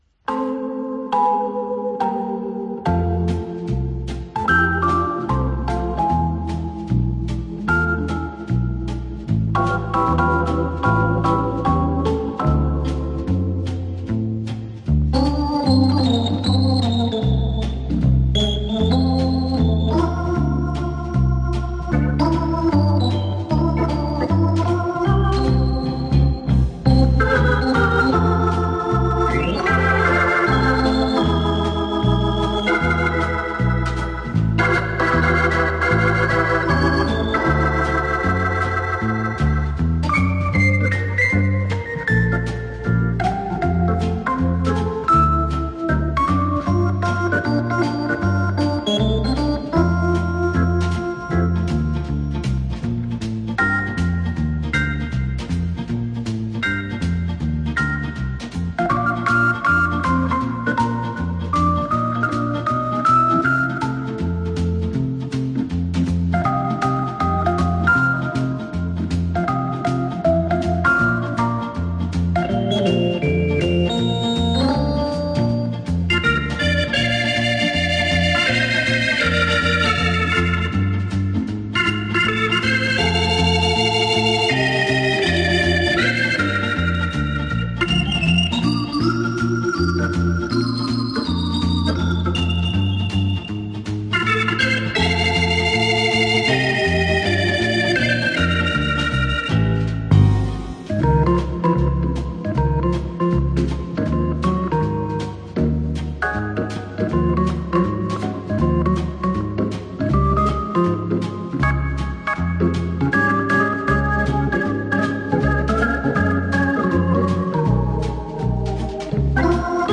Жанр: Easy Listening, Hammond